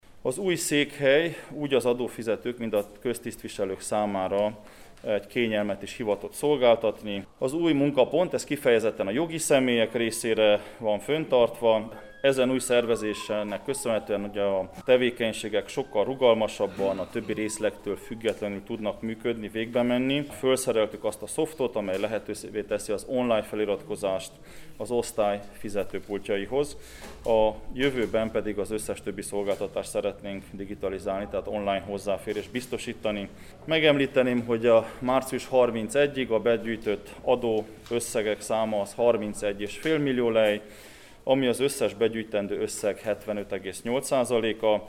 Soós Zoltán Marosvásárhely polgármestere elmondta, hogy elkezdődött az adó szolgáltatások digitalizásála is, felszerelésre került az a rendszer, ami lehetővé teszi az interneten való feliratkozást az adóosztály fizető pultjaihoz.